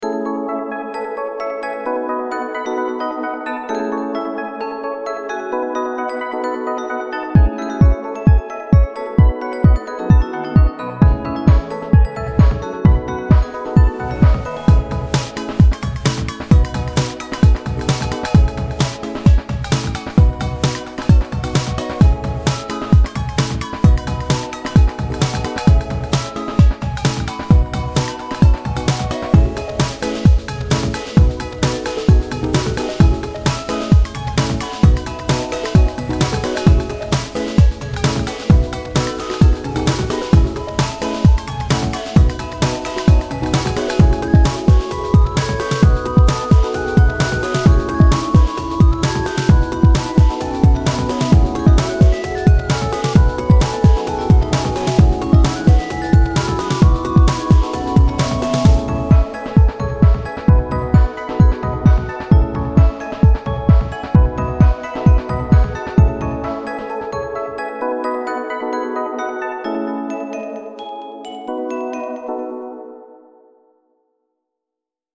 another frutiger aero ish song